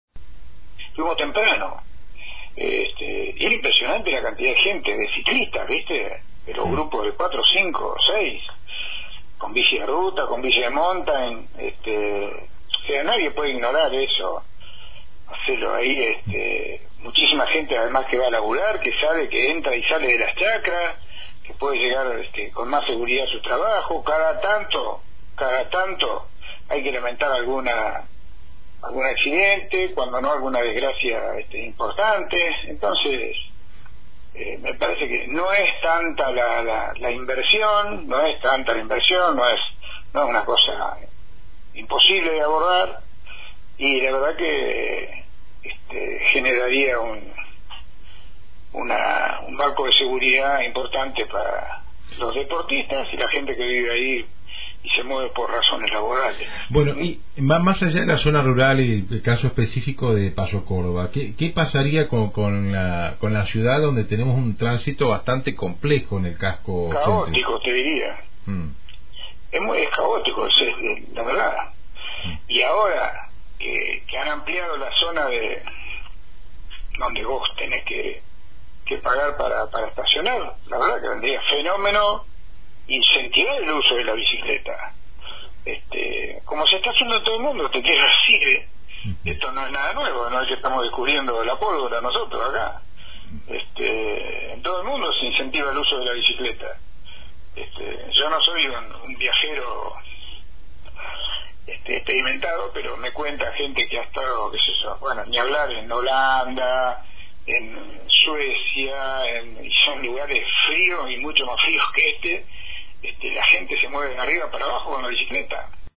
El ex concejal por Juntos Somos Río Negro de la ciudad de Roca, Mario Alvarez fue impulsor de la instalación de bicisendas en el área rural. Esto nos dice sobre la necesidad de avanzar en mas obras para ciclistas, tanto deportistas como para fines cotidianos:
Mario-Alvarez-ex-concejal-de-Juntos-Somos-Rio-Negro-en-la-ciudad-de-Roca.mp3